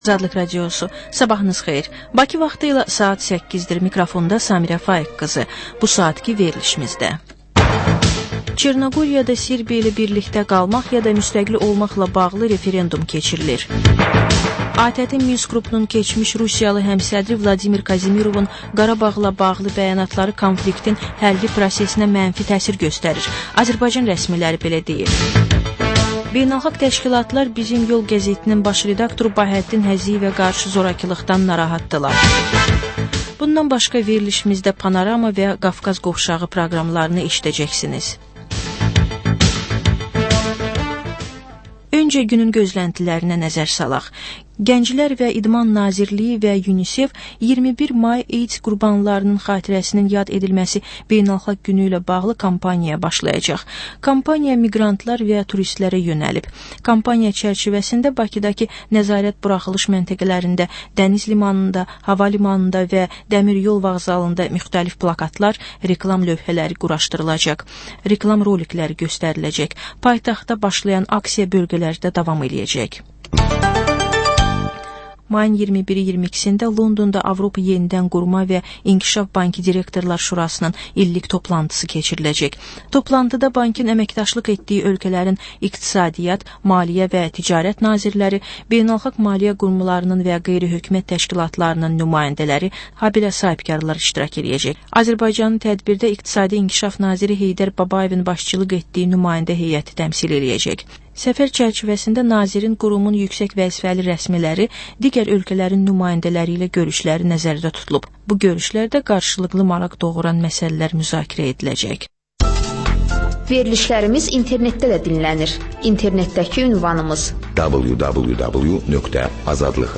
Hadisələr, reportajlar. Panorama: Jurnalistlərlə həftənin xəbər adamı hadisələri müzakirə edir. Və: Qafqaz Qovşağı: Azərbaycan, Gürcüstan və Ermənistandan reportajlar.